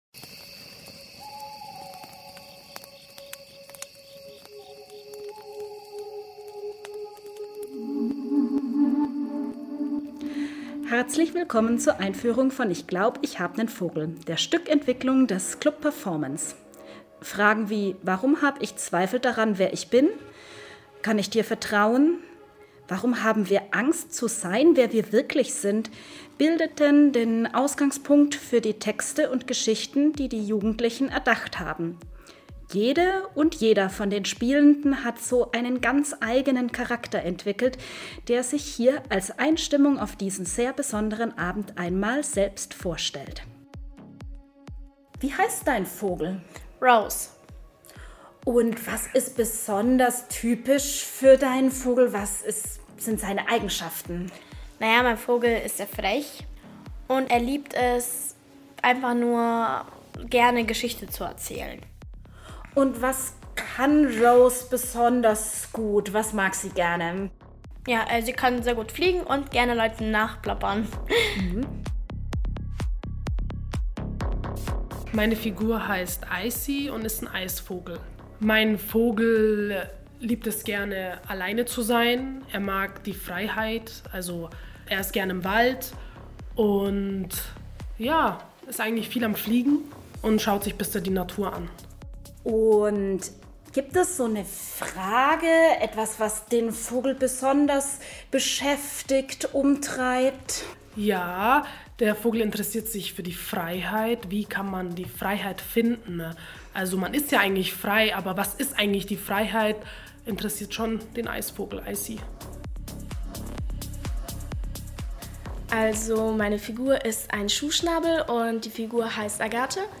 Audioeinführung • Player-Abrufsquelle: Staatstheater Augsburg